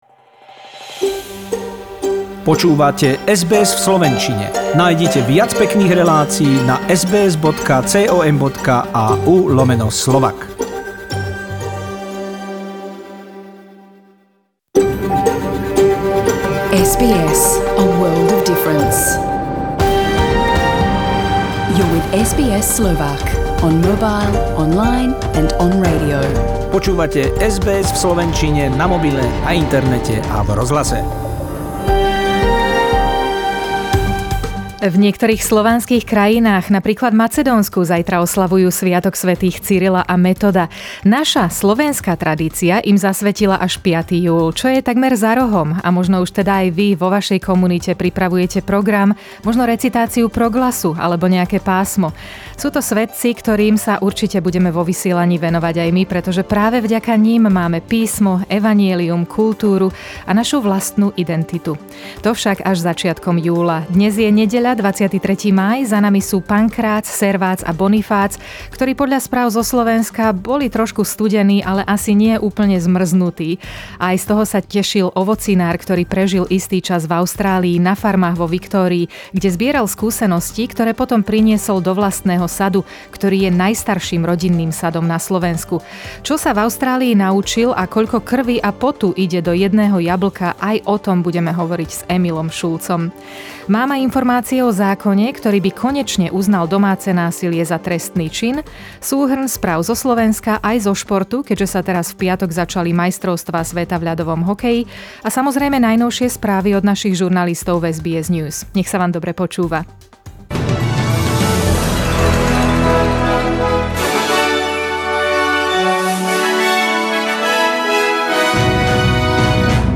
Listen to a summary of SBS news from Australia and the world 23/5/21